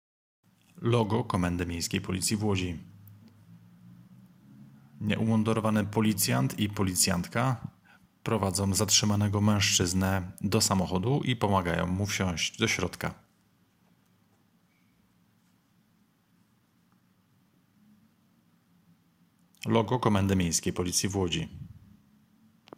Nagranie audio deskrypcja_filmu.m4a